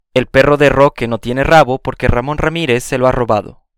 Trabalenguas com “r” e “j”
Por sua vez, o “r” inicial e o “rr” do espanhol tem um som um pouco diferente dos que ouvimos no português (uma dica para praticar esse som é imitar uma moto na frente do espelho).